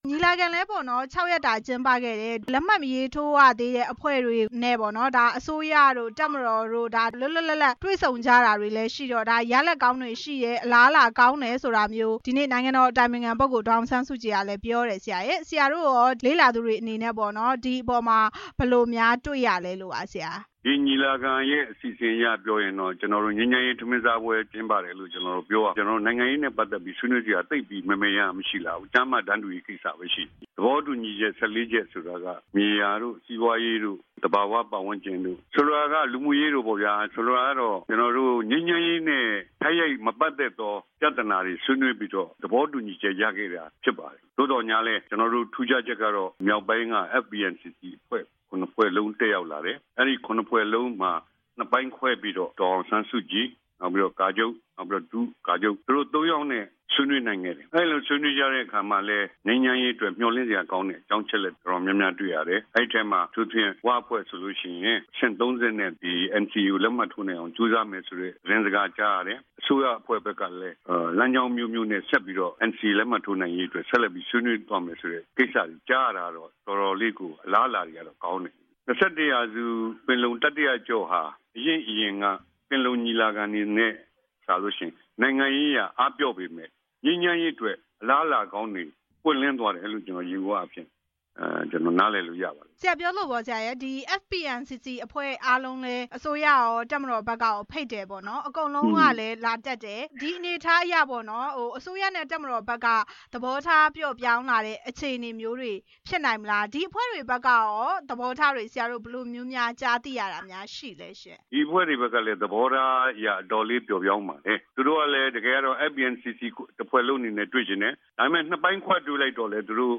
တတိယအကြိမ် ၂၁ ပင်လုံအကြောင်း ဆက်သွယ်မေးမြန်းချက်